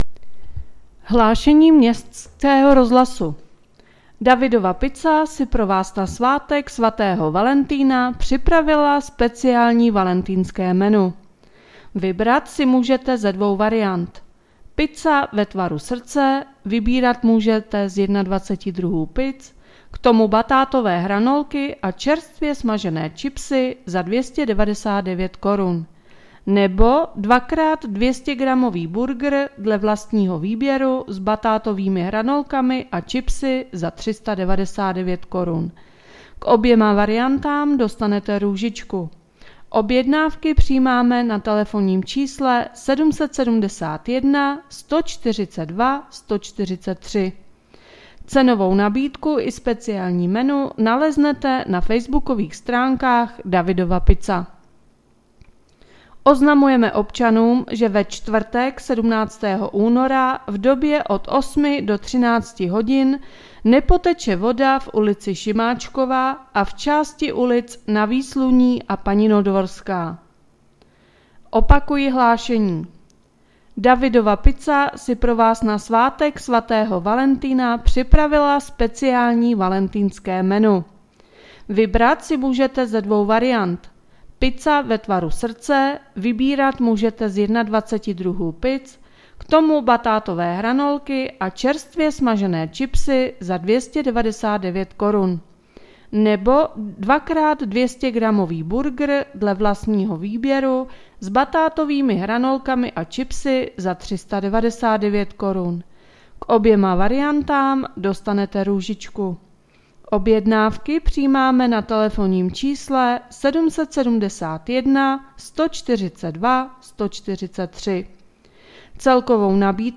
Hlášení městského rozhlasu 14.2.2022